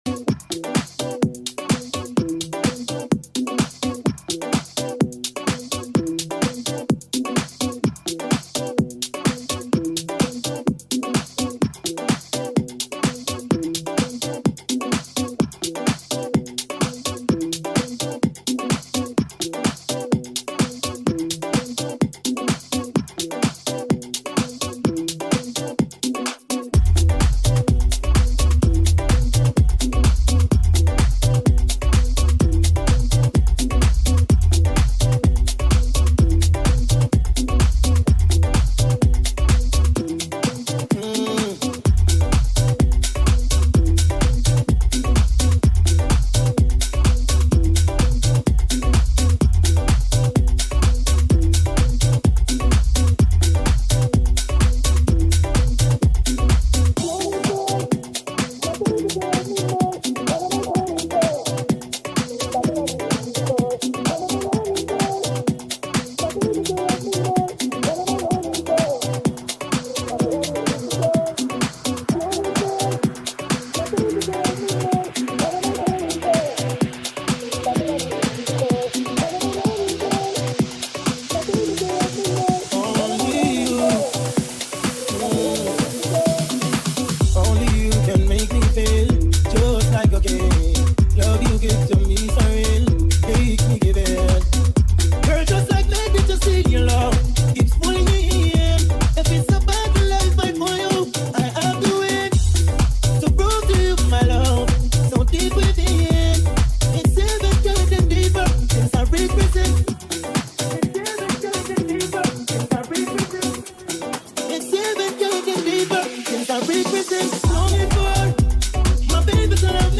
Also find other EDM Livesets, DJ Mixes
Liveset/DJ mix